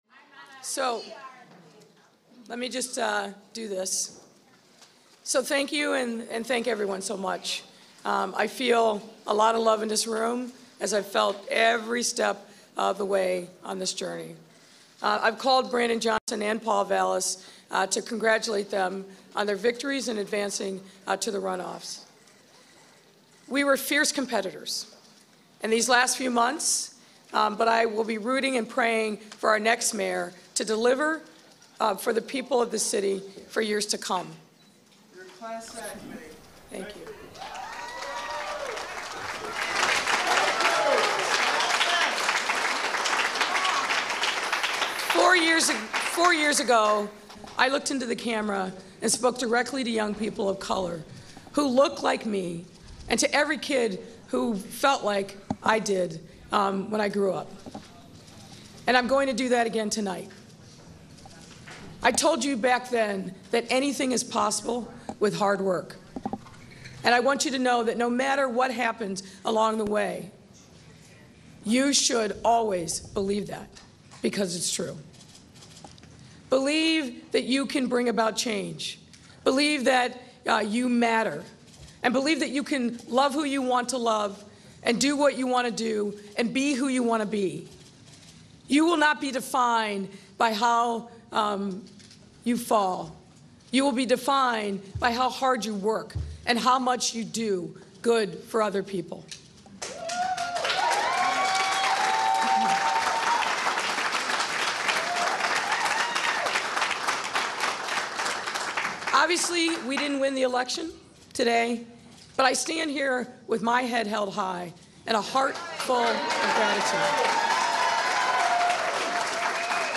Chicago Mayoral Concession Speech
Audio Note: Audio clip created from two discrete sources to render a complete set of delivered remarks.